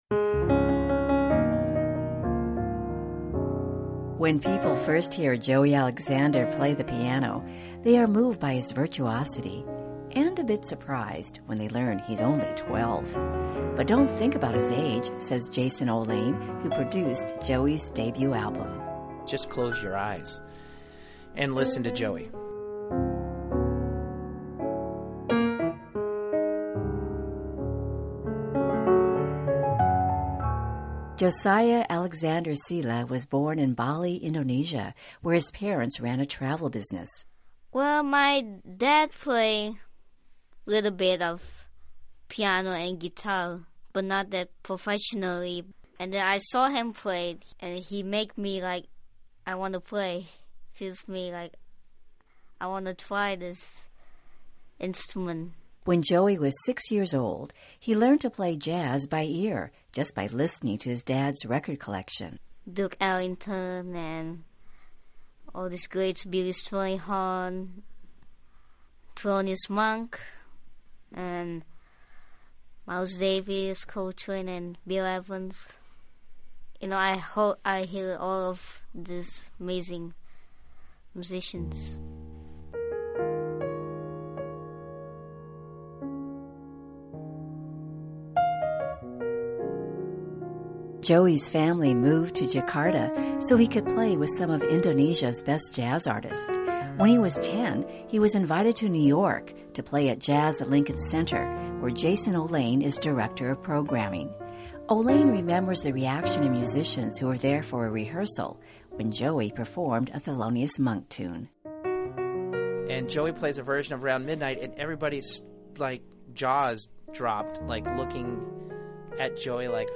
Listen to Joey Alexander play the piano, and you can hear the future of Jazz.